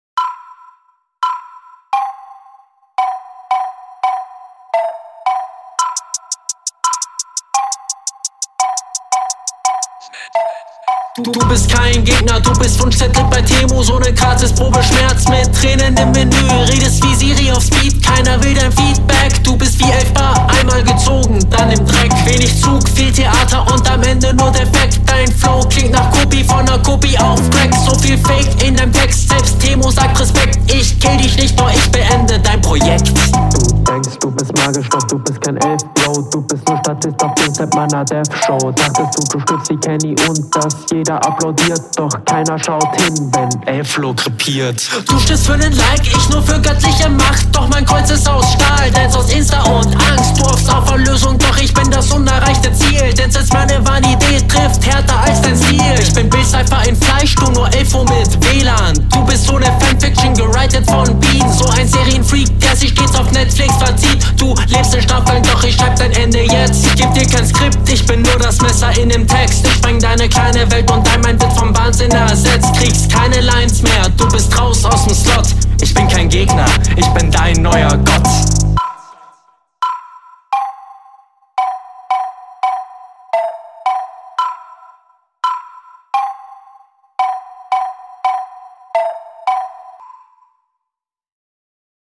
Auch hier kann ich die Soundquali lobend hervorheben (auch wenn mir 0:33-0:43 zu bassig in …